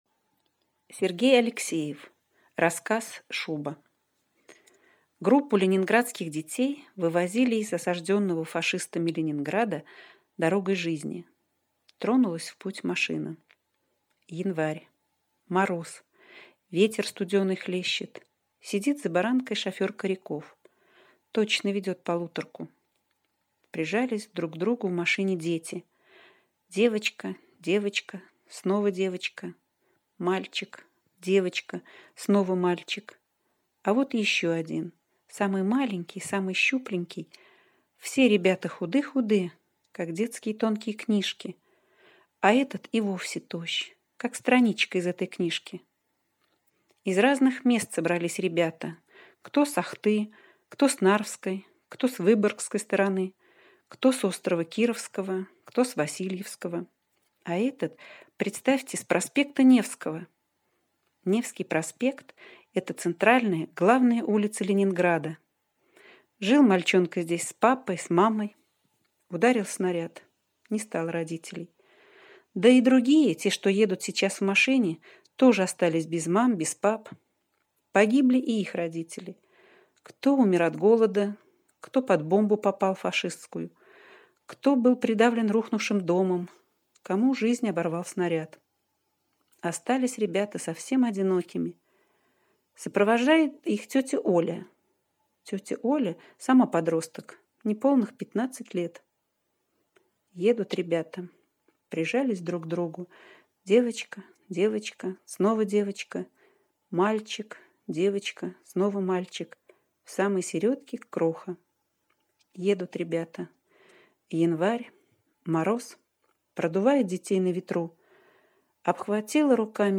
Аудиорассказ «Шуба»
Озвучка топ